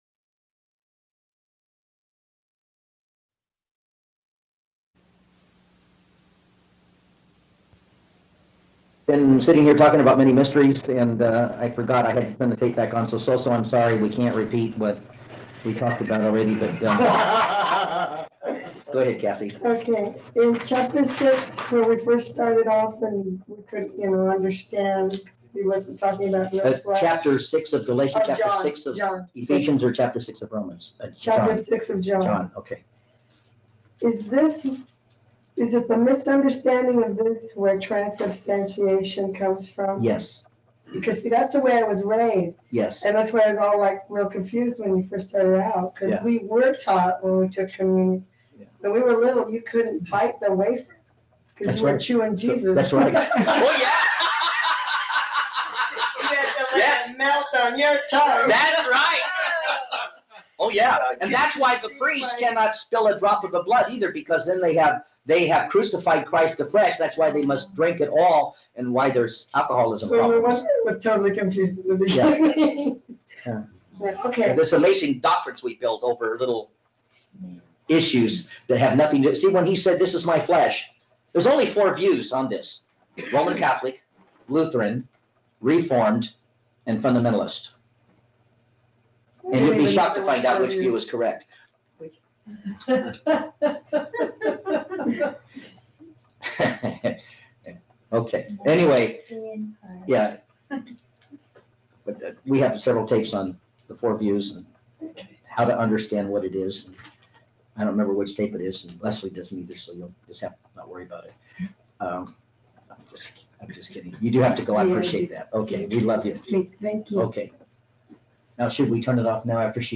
Archons (Principalities) Lesson nr 9